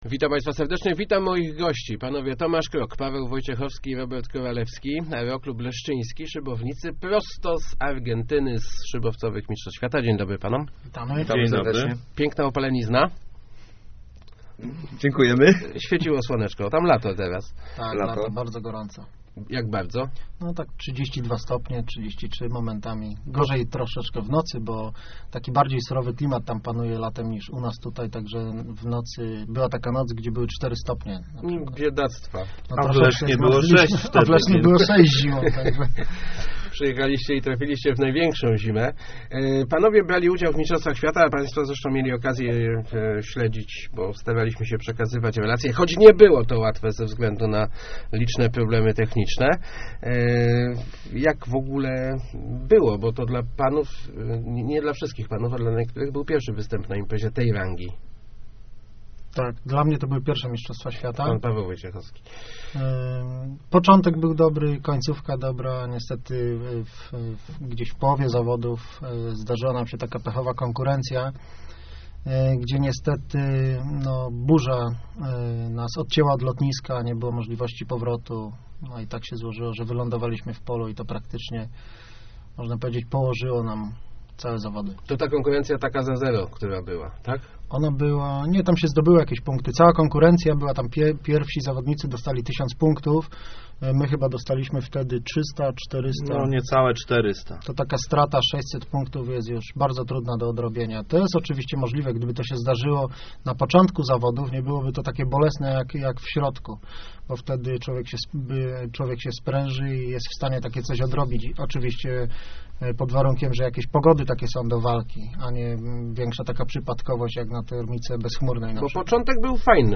Start arrow Rozmowy Elki arrow Prosto z Argentyny